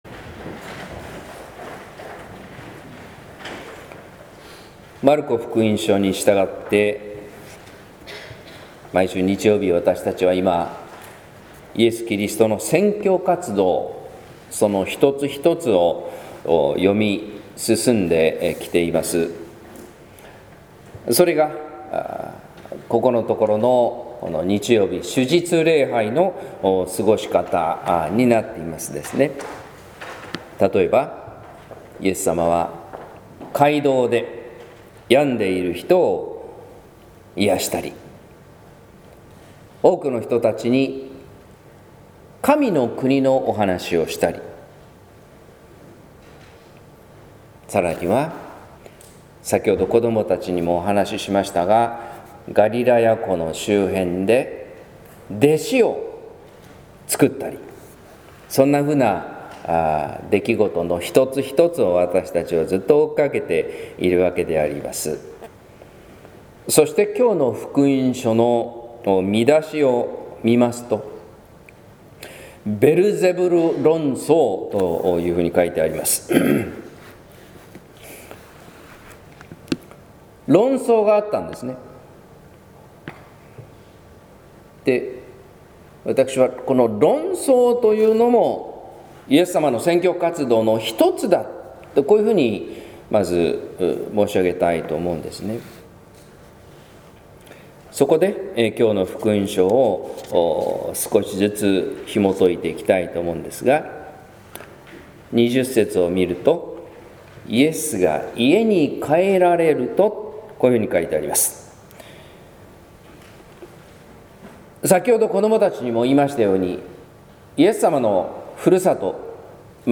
説教「熱く激しく生き抜いた男」（音声版） | 日本福音ルーテル市ヶ谷教会